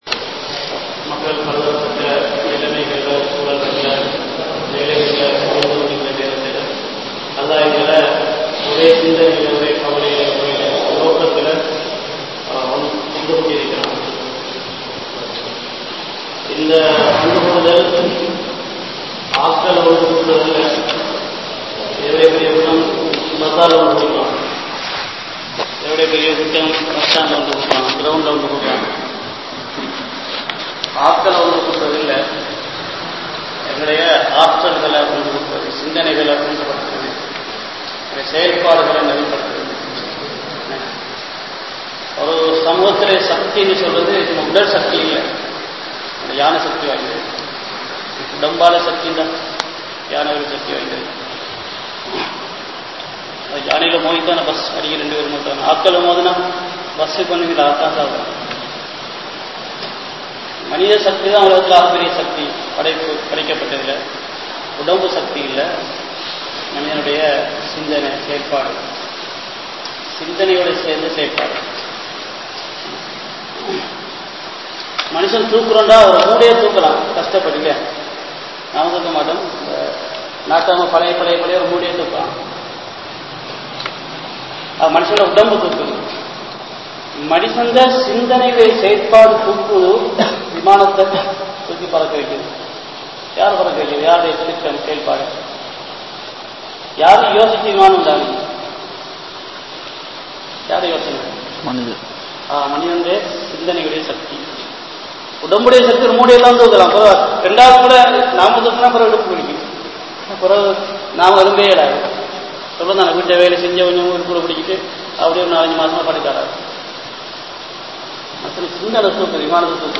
Marumaiyai Maranthu Vidaatheerhal (மறுமையை மறந்து விடாதீர்கள்) | Audio Bayans | All Ceylon Muslim Youth Community | Addalaichenai